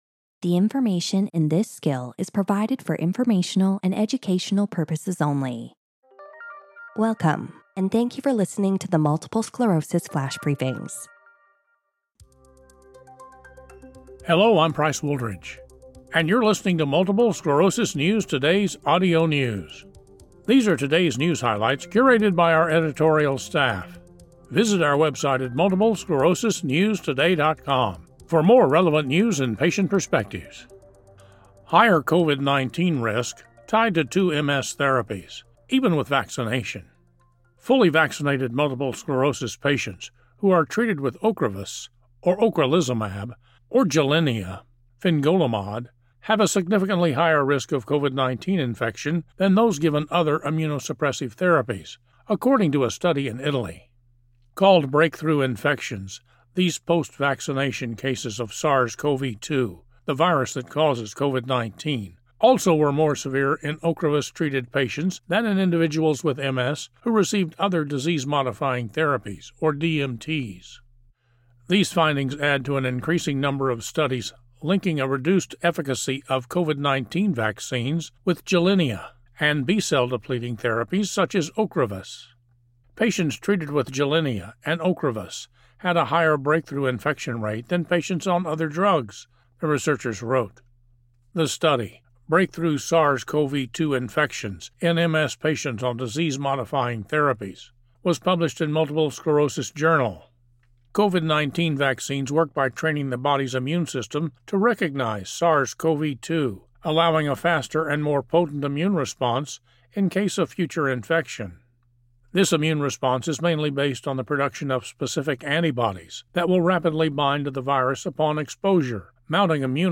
reads an article about how fully vaccinated MS patients who are taking Ocrevus or Gilenya have a higher risk of COVID-19 infection than those on other therapies.